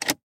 Вы найдете различные варианты щелчков и клацанья выключателя: от классических резких до современных мягких.
Выключение рубильника